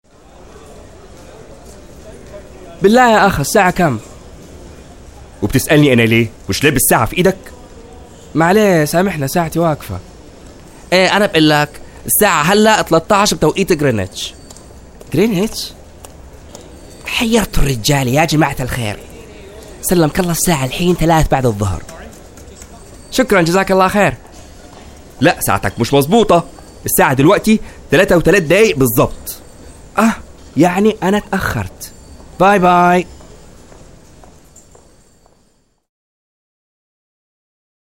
Male
30s
Arabic (Native) , American English , British English
Assured, Bright, Character, Confident, Cool, Corporate, Engaging, Friendly, Natural, Warm, Versatile
Voice reels
Microphone: Shure PG42 USB